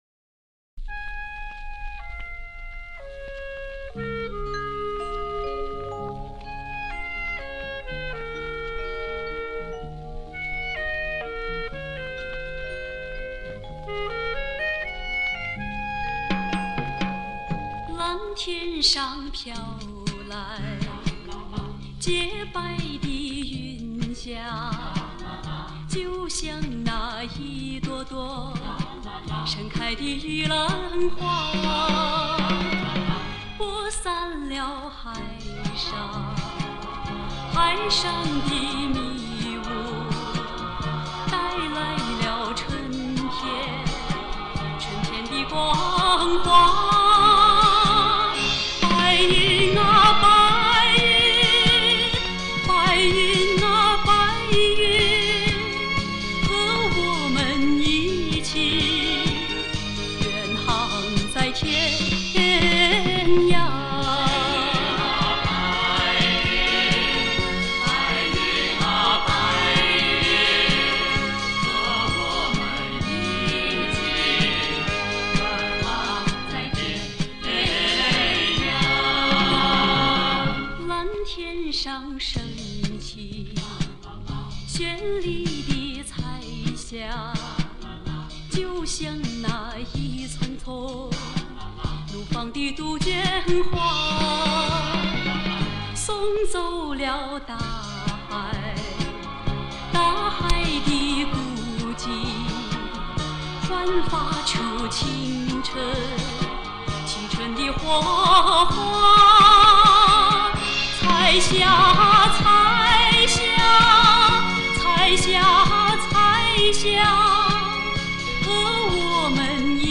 这是原片配乐版，这个版本疑似没有再版。